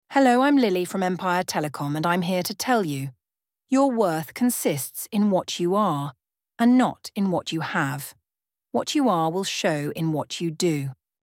Empire Telecom offers its customers free, professionally recorded auto-attendant greetings and voicemail messages.
Female